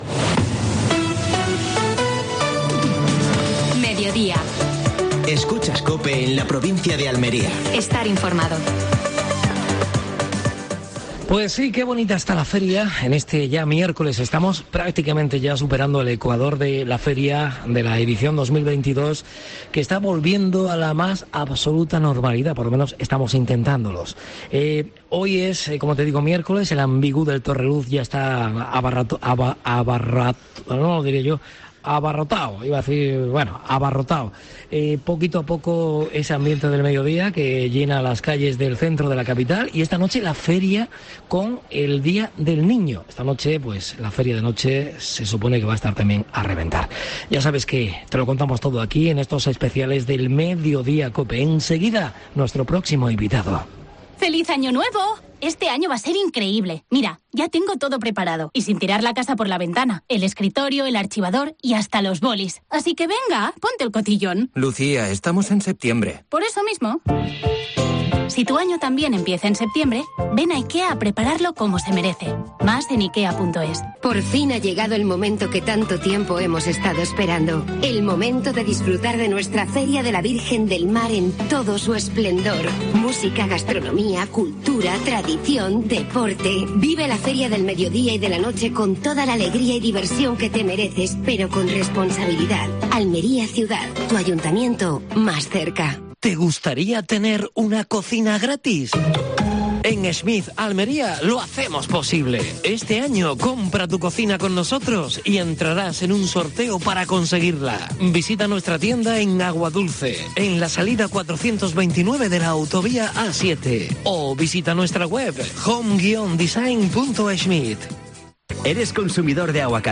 Feria de Almería.